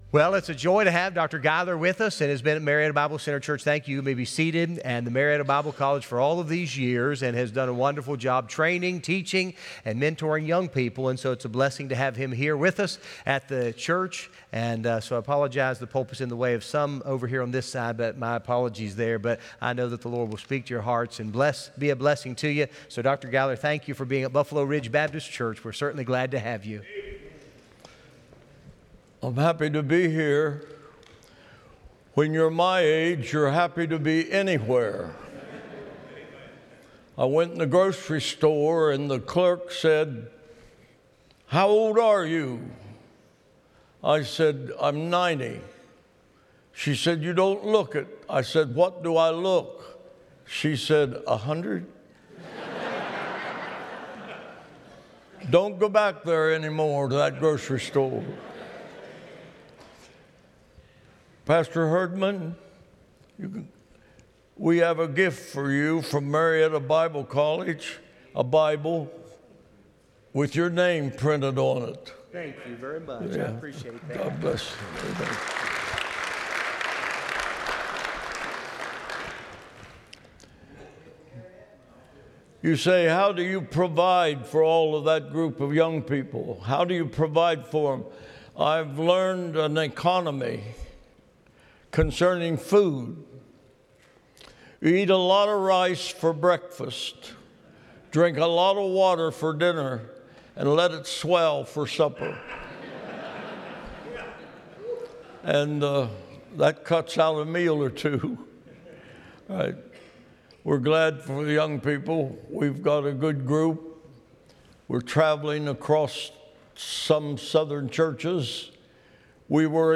Tuesday Evening Service